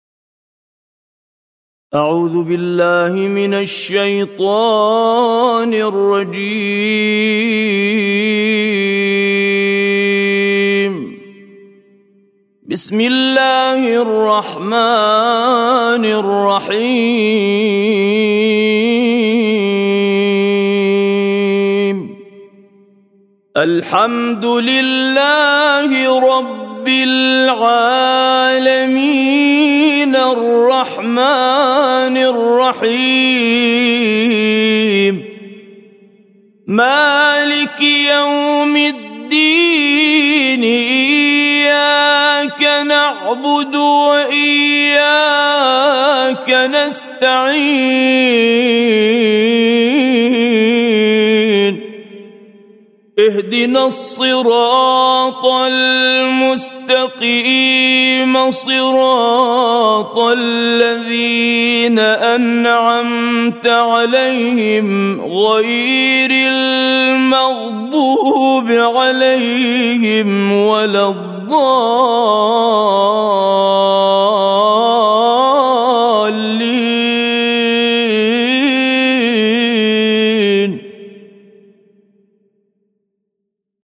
طور عراقي